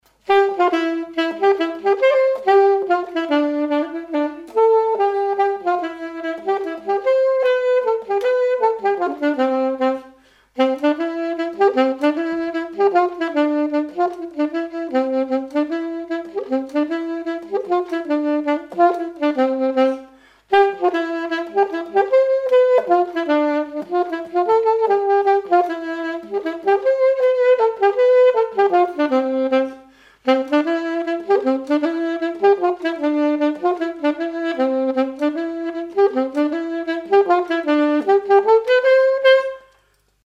Vendée
Chants brefs - A danser
danse : scottich trois pas
Pièce musicale inédite